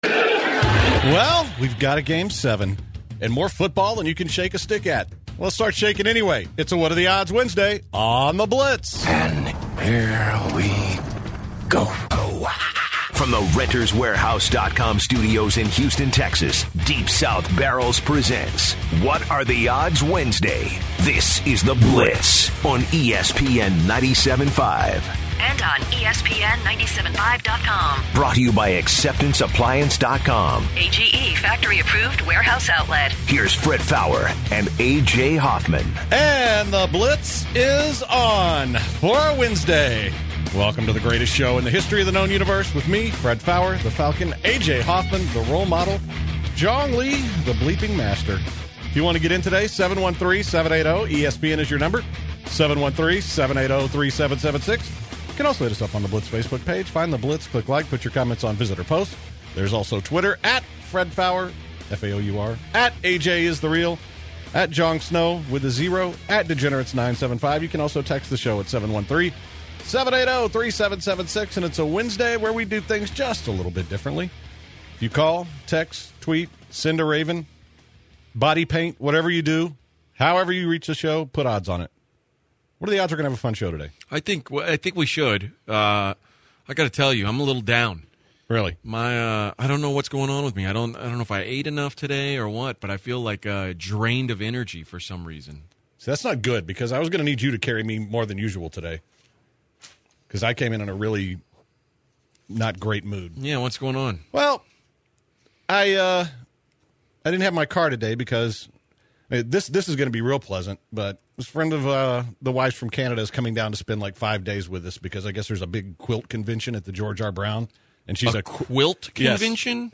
The guys talked about the world series and took what are the odds calls.